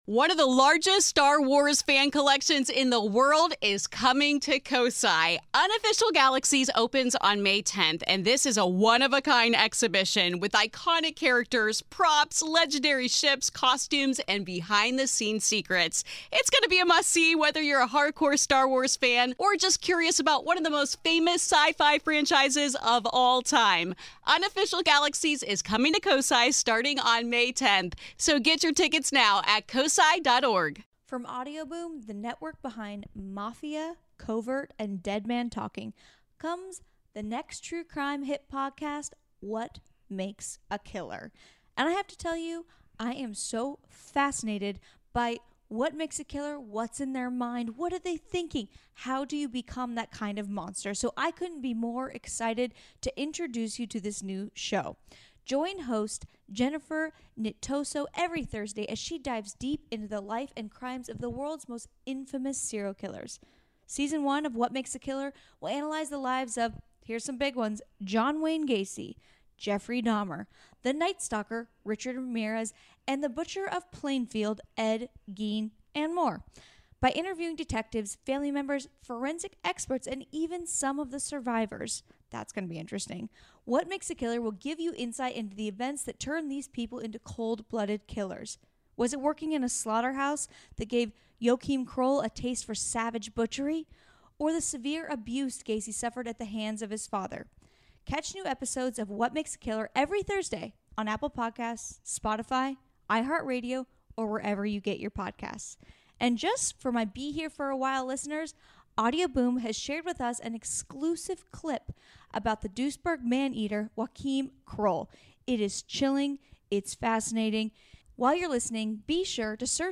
Listen to new episodes of What Makes a Killer for a look into the life and crimes of the world’s most infamous serial killers. Featuring interviews with family members, forensic experts, law enforcement, and witnesses.